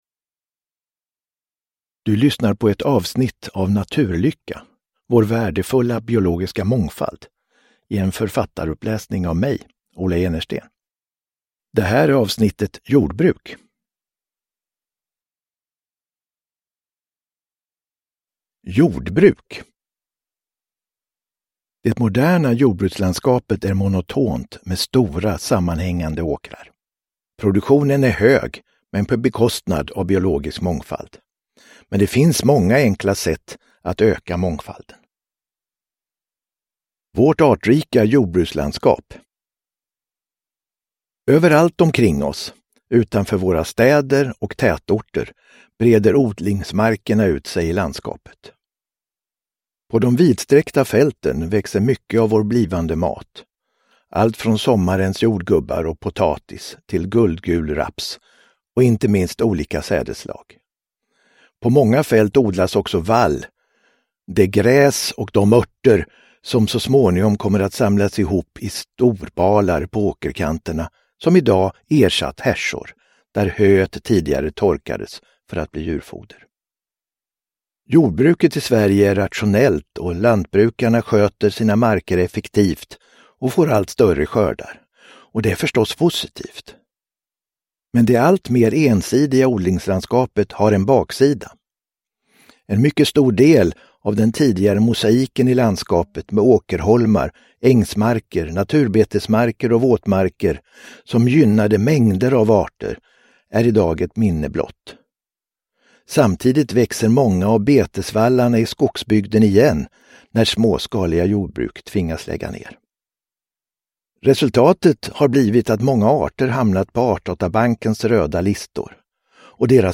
Naturlycka - Jordbruk – Ljudbok – Laddas ner